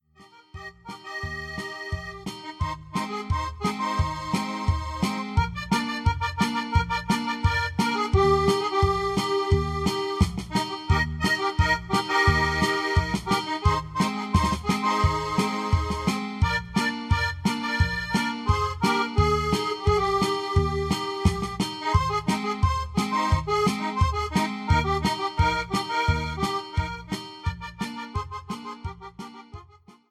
109-Polca-Nte.mp3